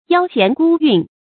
幺弦孤韻 注音： ㄧㄠ ㄒㄧㄢˊ ㄍㄨ ㄧㄨㄣˋ 讀音讀法： 意思解釋： 謂小曲的音韻，與具備各種樂曲樂器的大樂相對。